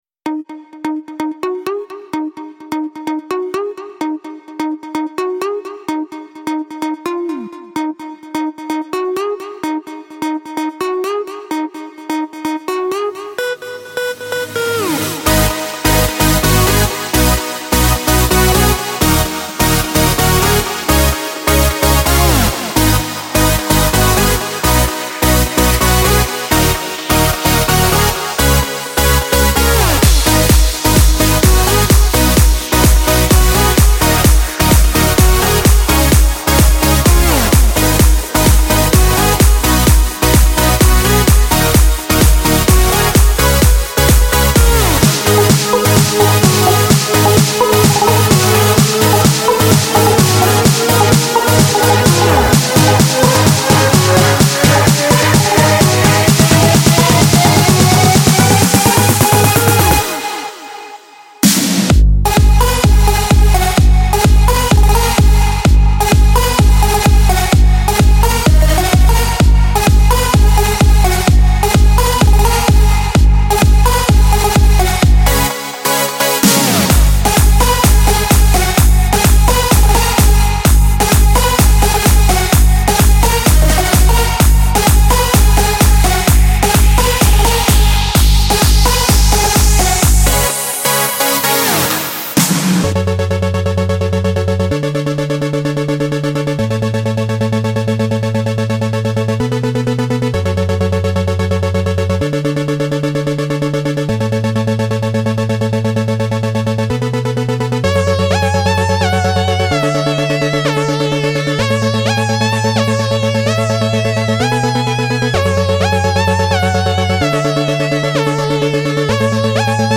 • 6 Construction Kits (128 BPM)